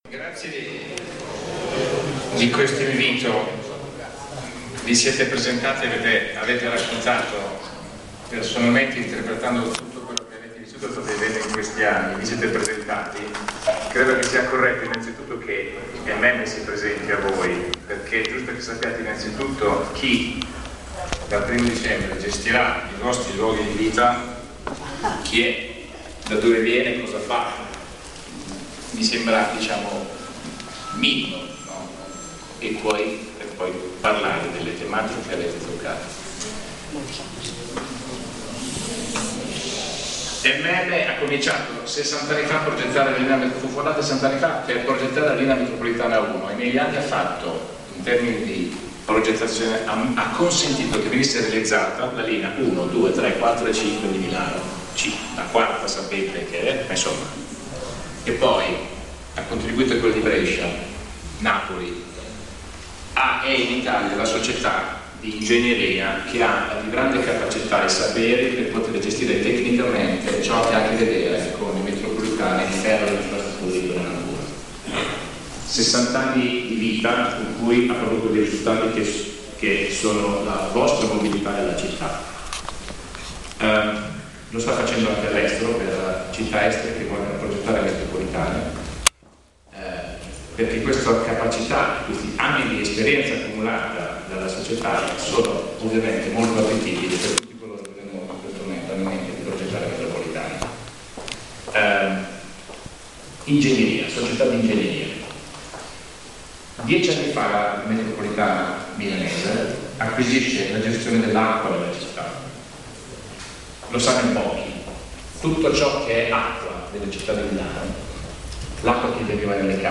una partecipata assemblea promossa dal CO.A.A.S (Comitati e Associazioni per l’Autorganizzazione Sociale)